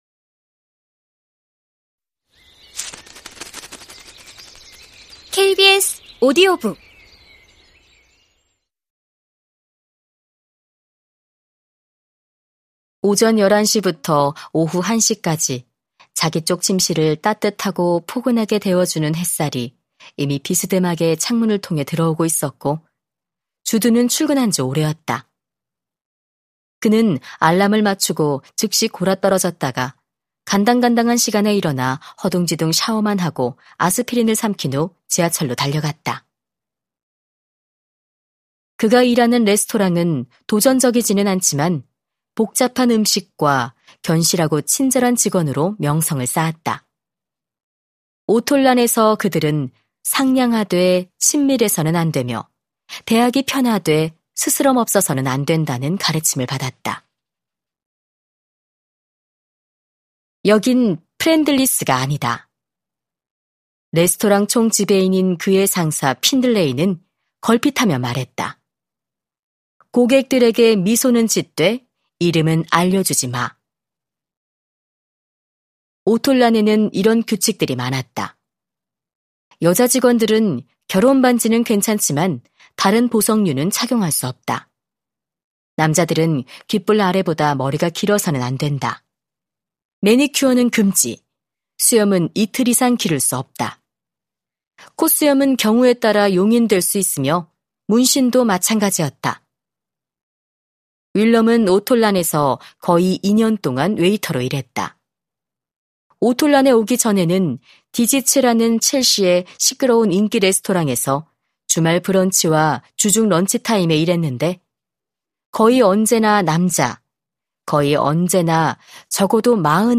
KBS오디오북_리틀 라이프_한야 야나기하라_성우